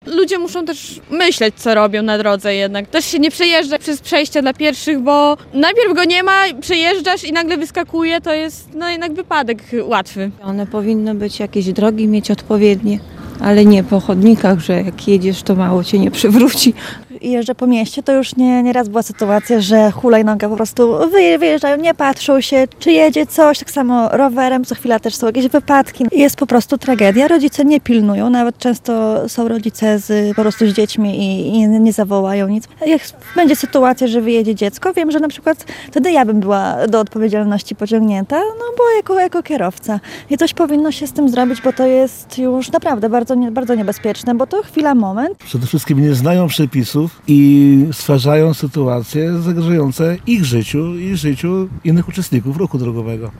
Rozpędzone hulajnogi są ogromnym zagrożeniem na ulicach – przyznają jednogłośnie mieszkańcy Łomży: